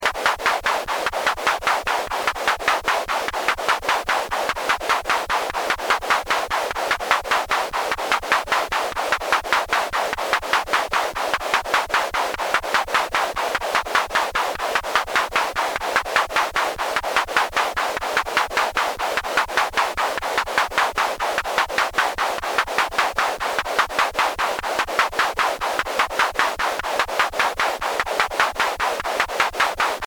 This article gives seven examples of sounds you can produce with Sound Lab, the simple single-board analogue synthesiser that I described in the earlier article Sound Lab - a Simple Analogue Synthesiser:
Irregular drumming
drumming.mp3